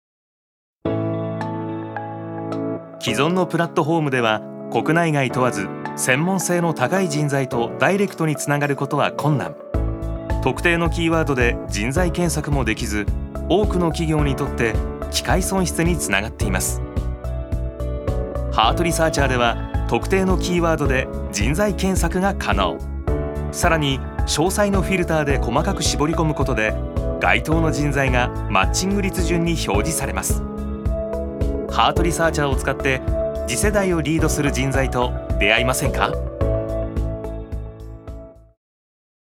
所属：男性タレント
ナレーション３